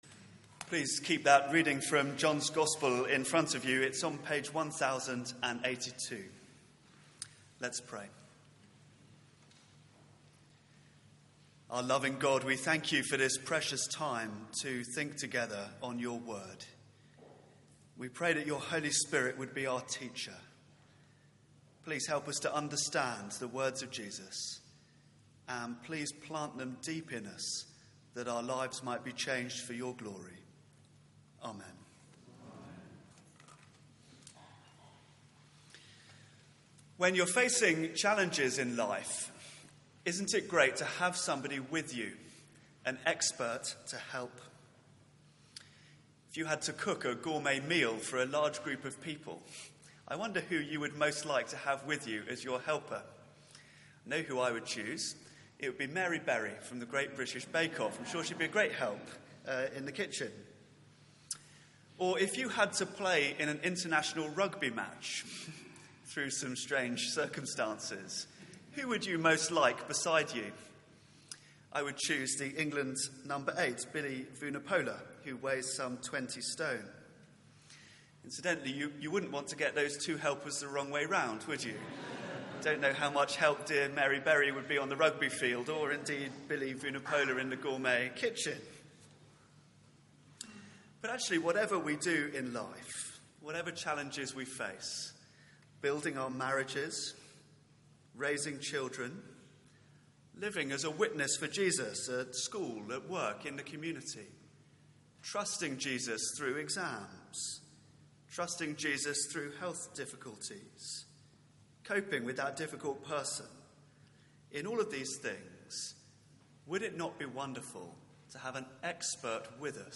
Media for 9:15am Service on Sun 05th Jun 2016
Theme: Isolated but indwelt Sermon